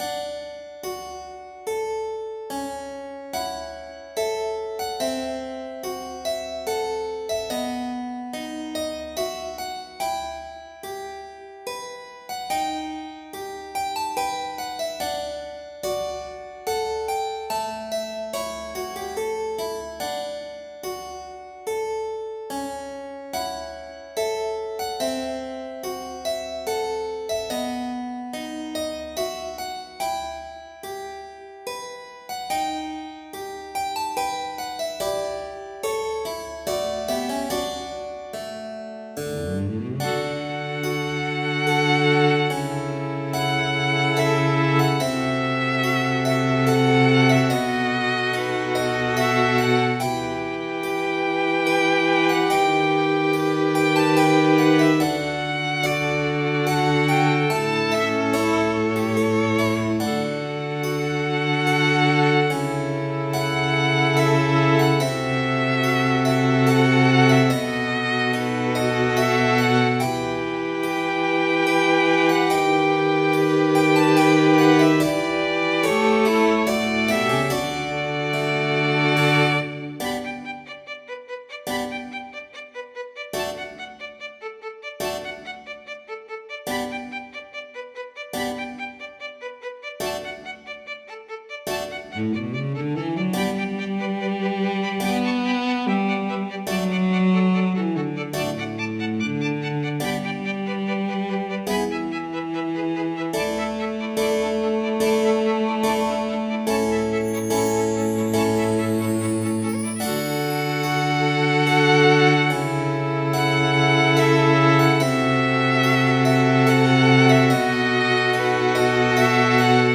落ち着いた図書館。